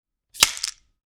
Part_Assembly_61.wav